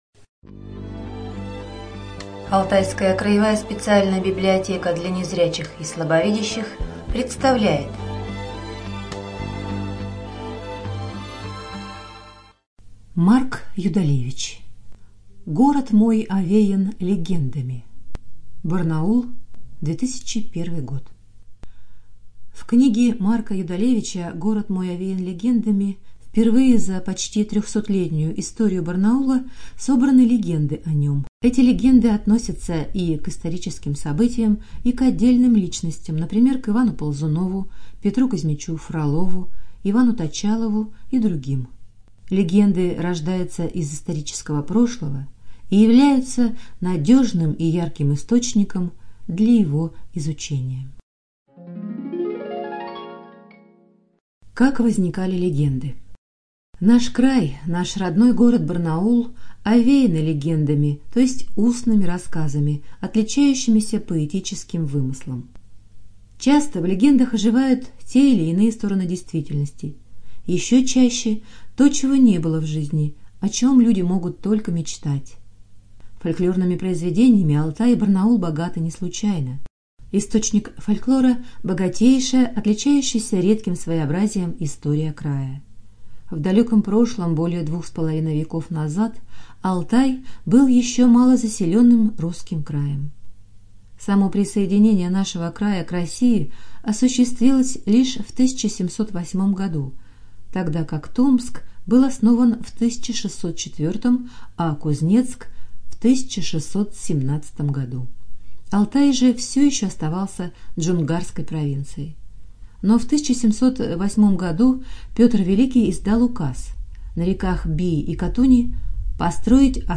Студия звукозаписиАлтайская краевая библиотека для незрячих и слабовидящих